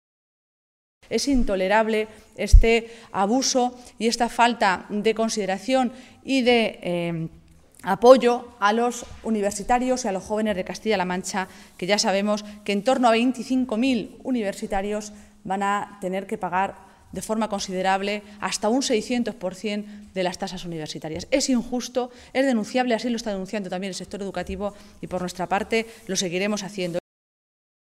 Así se pronunciaba Maestre en una comparecencia ante los medios de comunicación en la que decía que “en las cinco tristes páginas que tiene esa circular se pone por escrito, negro sobre blanco, lo que la señora Cospedal y el consejero luego niegan o matizan en sus declaraciones, y es el desmantelamiento de la educación pública en Castilla-La Mancha”, señalaba.